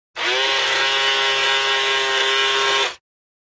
Small toy motor spins at variable speeds. Motor, Toy Buzz, Motor